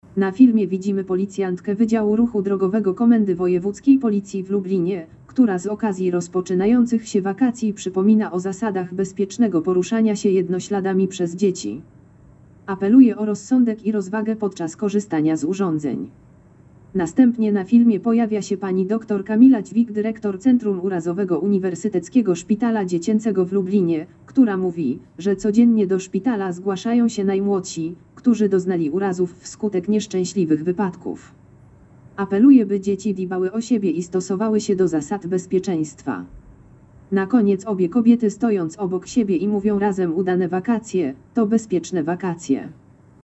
Nagranie audio Audiodeskrypcja filmu Bezpiecznie przez wakacje.